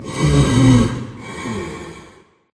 Sound / Minecraft / mob / blaze / breathe2.ogg
breathe2.ogg